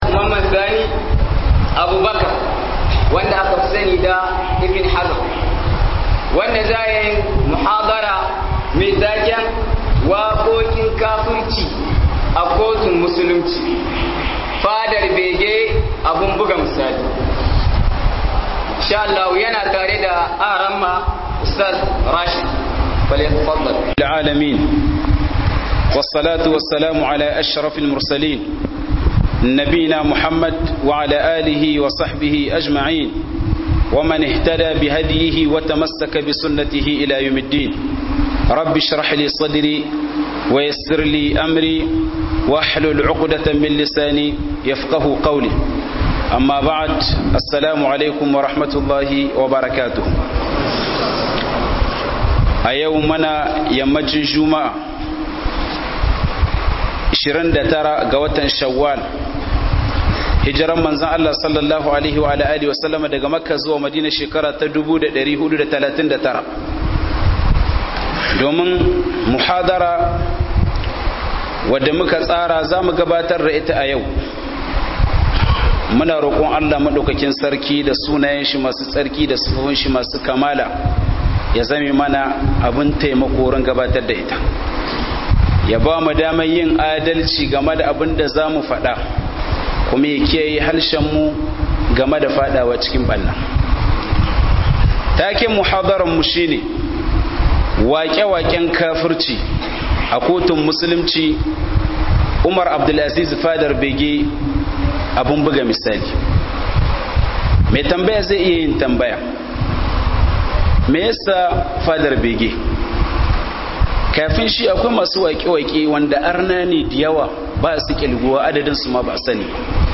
Wakokin kafirci Fadar bege~1 - MUHADARA